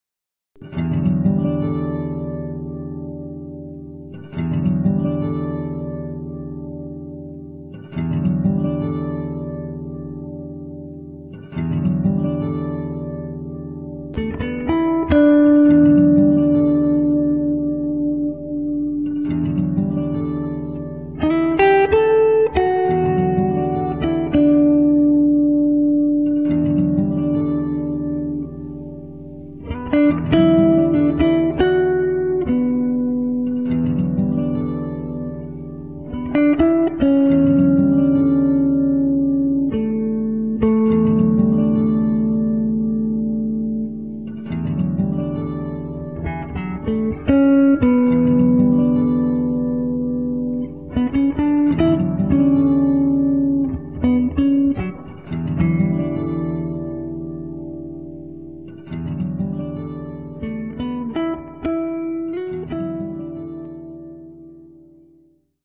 Basically, a warm, very gentle piece of guitar playing.